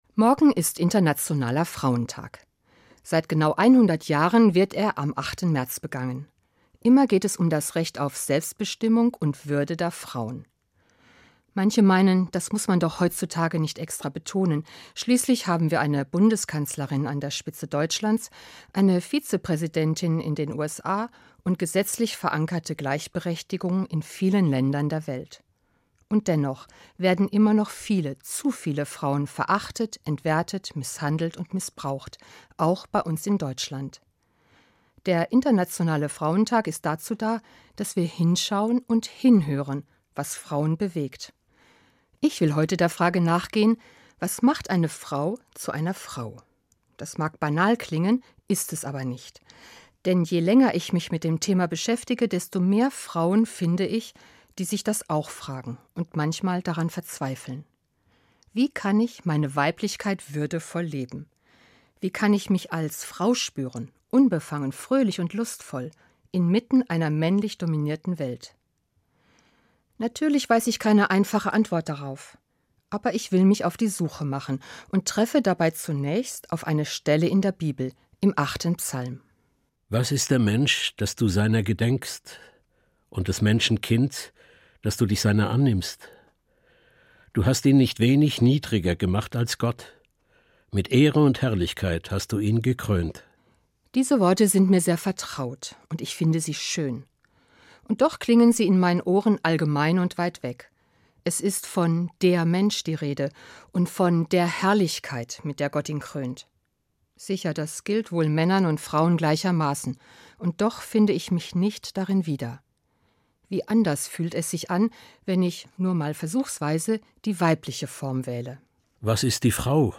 Evangelische Pfarrerin